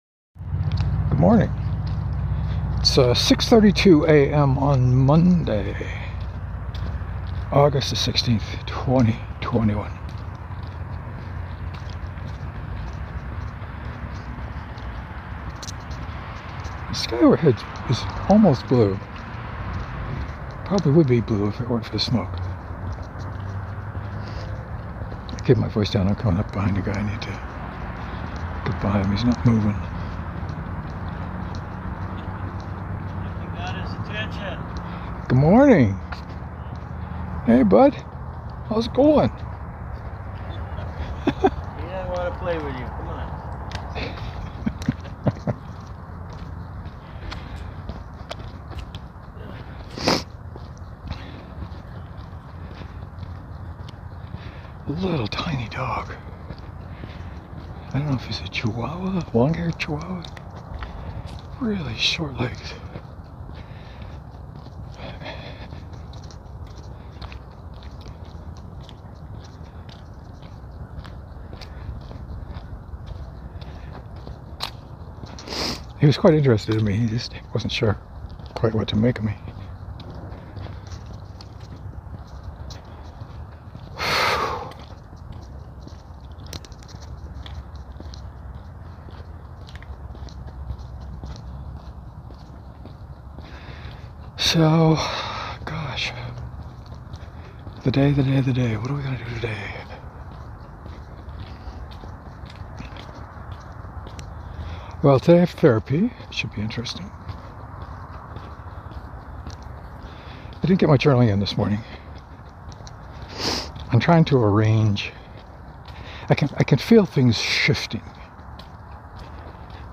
Lots of boot sounds.